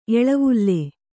♪ eḷavulle